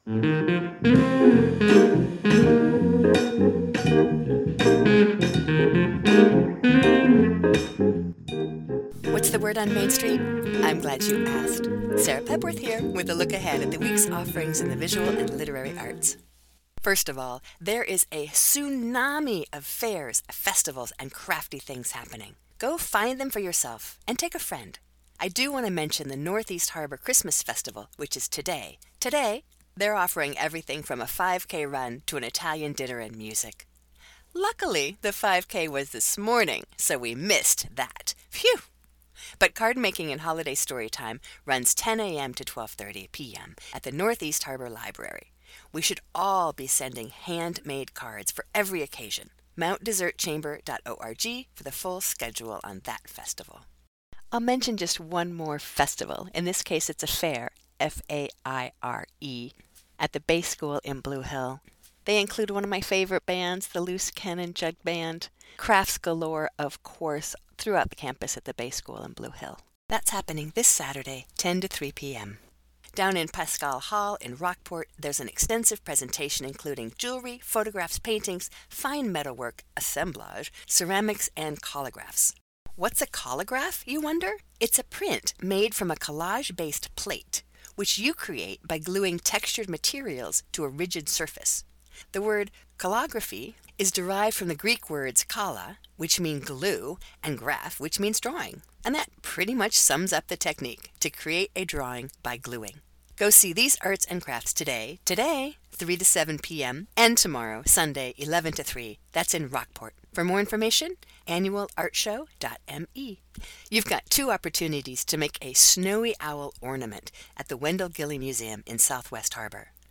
Infinite Blues is a cut from his recently released neon night, an excursion into an ambient/electronic musical world built around rhythmic bass ostinatos, clouds of processed looping electronic atmospheres, and melody.